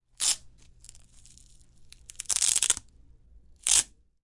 描述：Foley Final Audio1 2018